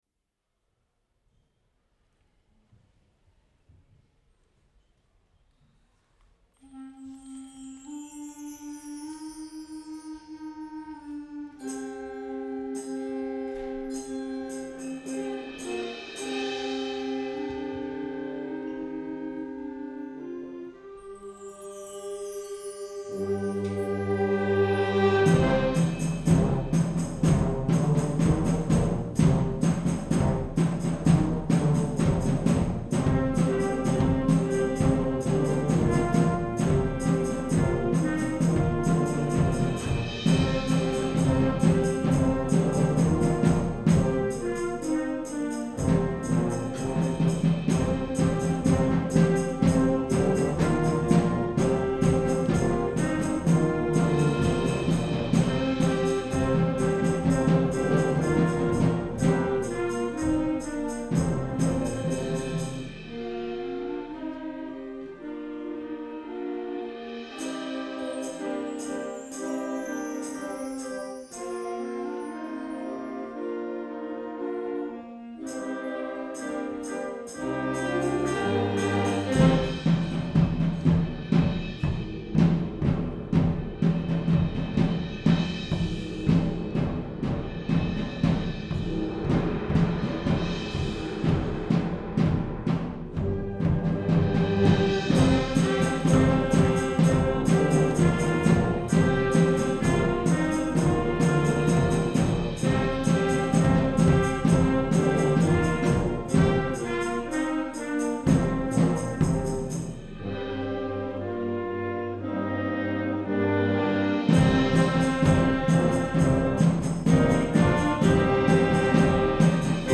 Rabenstein / Pielach
Junior-Band